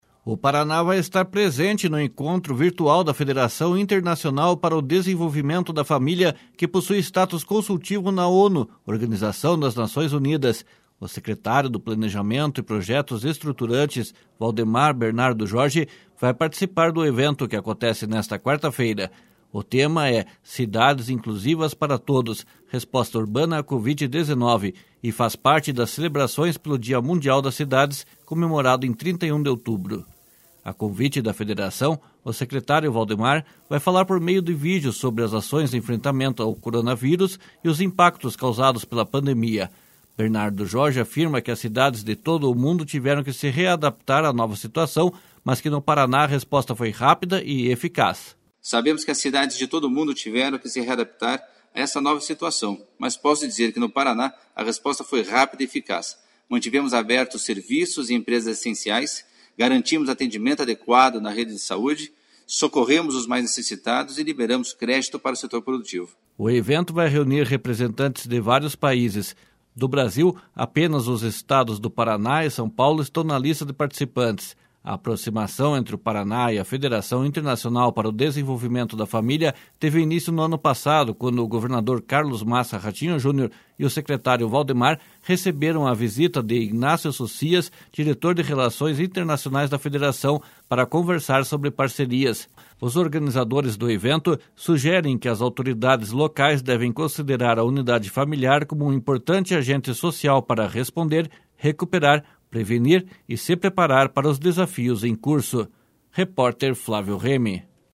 A convite da Federação, o secretário Valdemar vai falar por meio de vídeo, sobre as ações de enfrentamento ao coronavírus e os impactos causados pela pandemia.
//SONORA VALDEMAR BERNARDO JORGE//